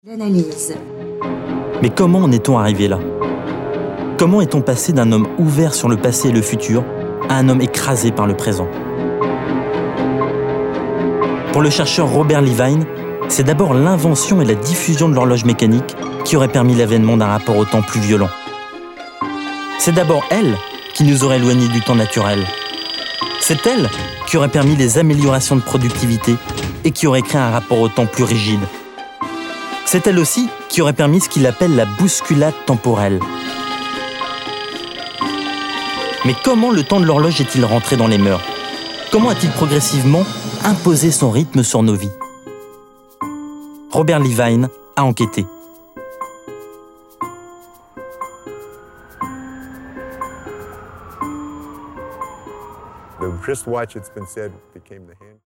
Voix off homme grave institutionnel tv
Sprechprobe: eLearning (Muttersprache):
Voice over man medium bass tv